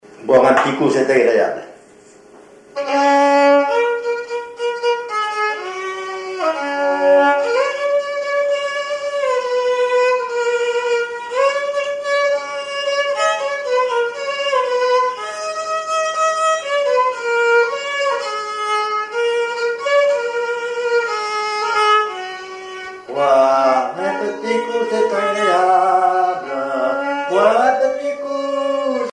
violoneux, violon
danse : marche
Pièce musicale inédite